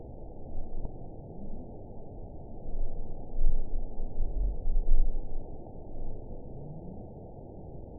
event 922547 date 01/29/25 time 08:10:26 GMT (3 months ago) score 9.35 location TSS-AB06 detected by nrw target species NRW annotations +NRW Spectrogram: Frequency (kHz) vs. Time (s) audio not available .wav